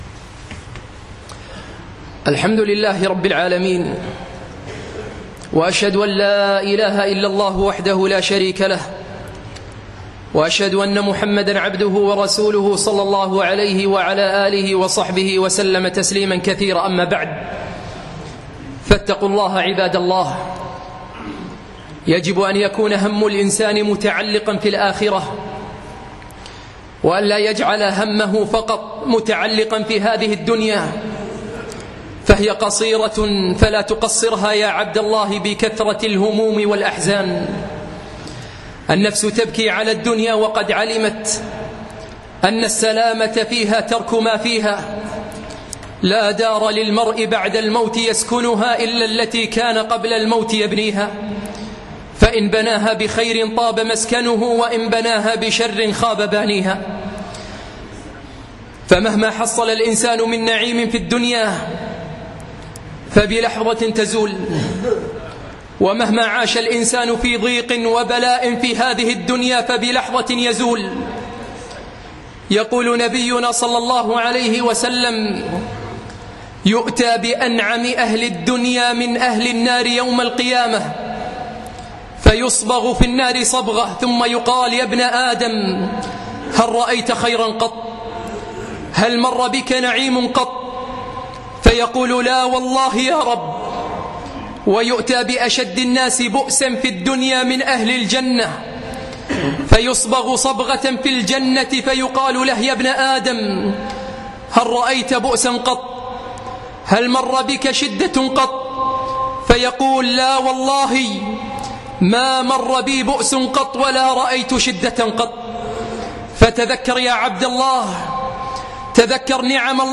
مقتطف من خطبة بعنوان كيف تكون سعيدا ؟